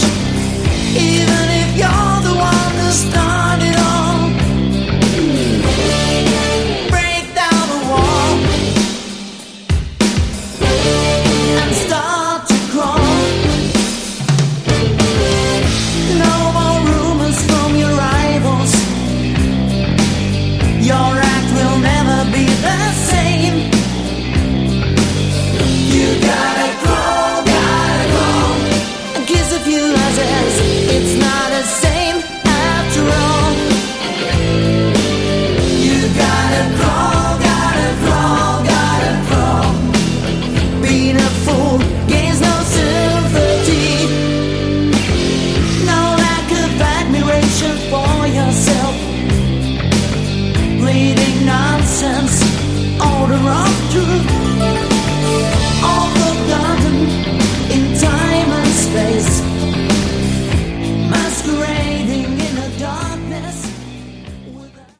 Category: Hard Rock
lead vocals
electric and acoustic guitars
bass, synthesizers, vocals
keyboards, harmonica